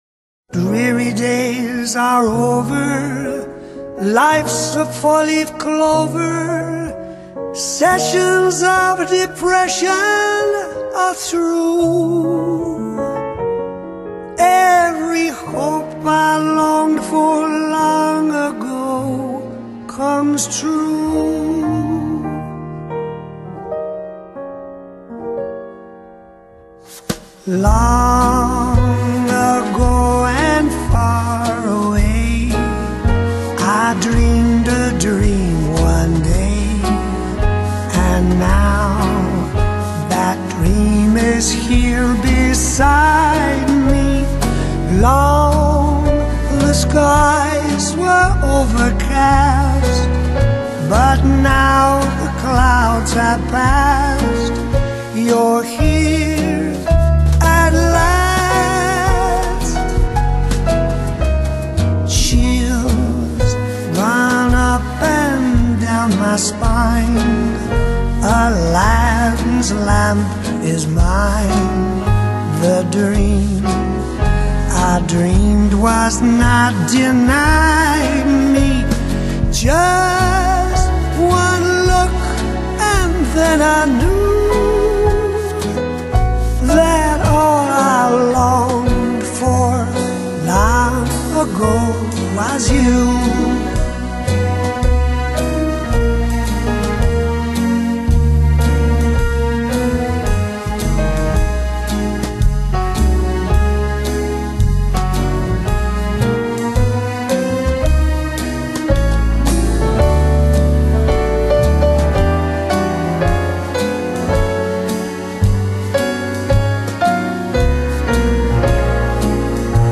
以風格統一的性感白人爵士，演唱世間男女傳唱的愛戀情歌經典。